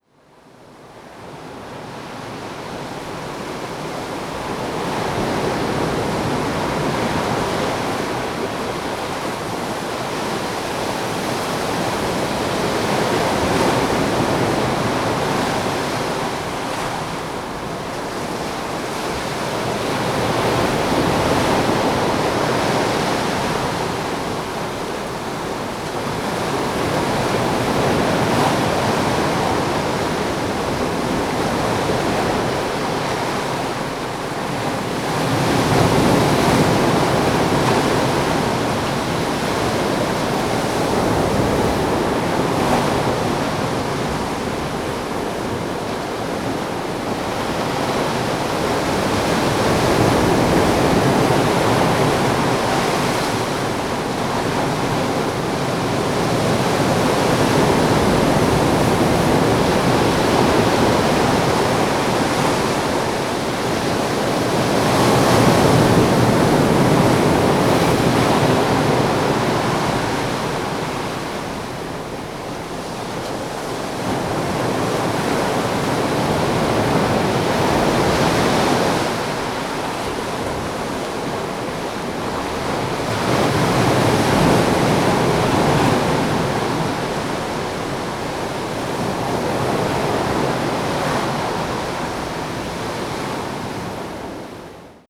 Recordings from Juan de Fuca Provincial Park
56. Mystic Beach waves
56_Mystic_Beach_waves.wav